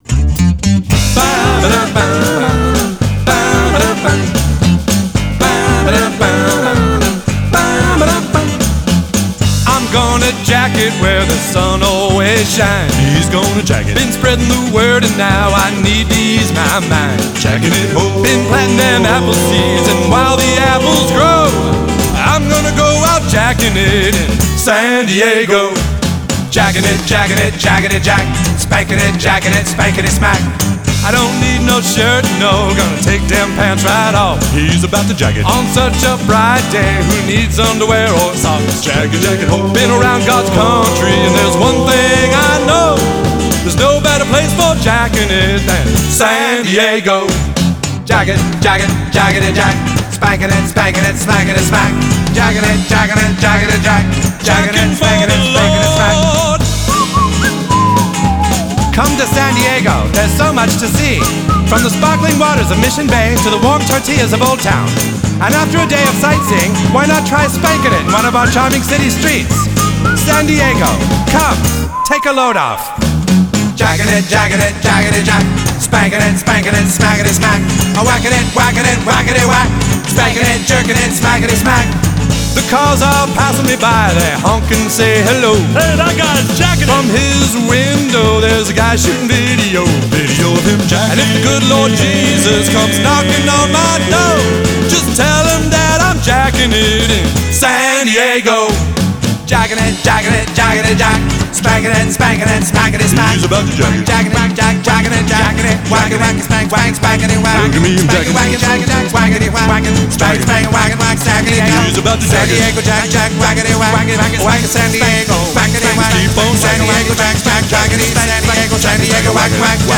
BPM110-113
MP3 QualityMusic Cut